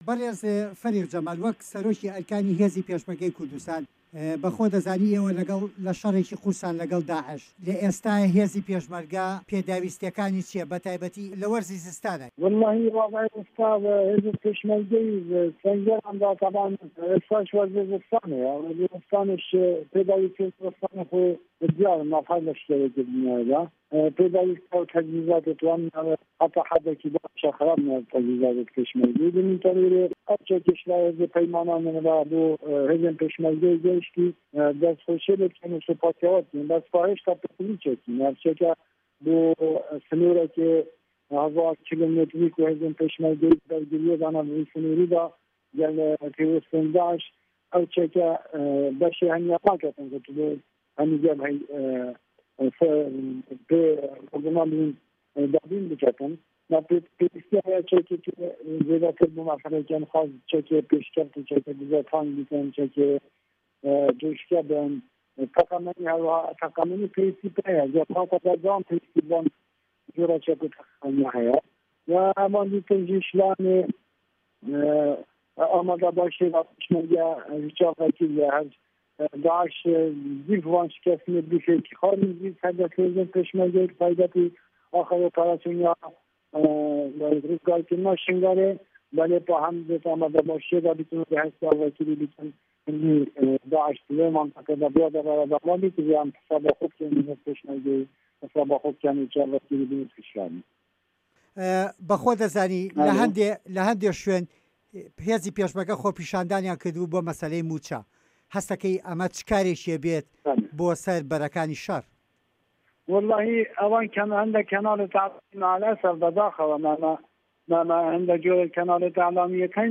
وتووێژ لەگەڵ فەریق جەمال موحەمەد